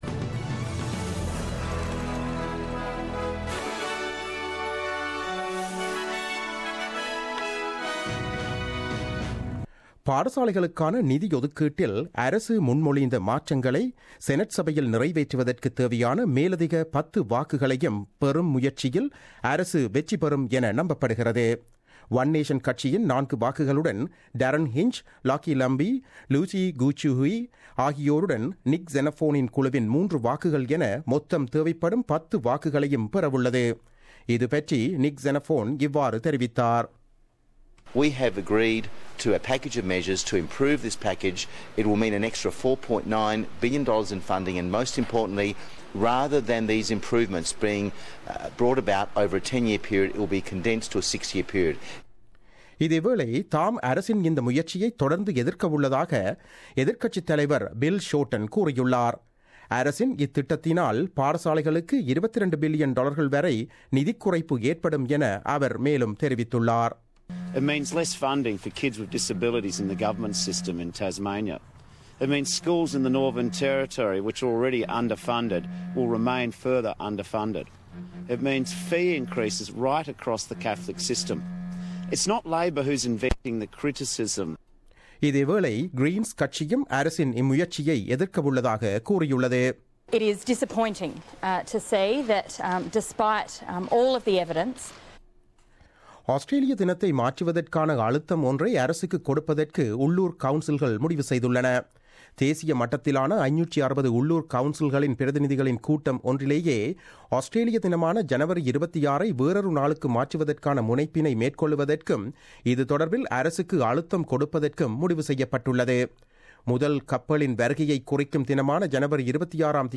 The news bulletin broadcasted on 21 June 2017 at 8pm.